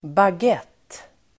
Uttal: [bag'et:]
bagett.mp3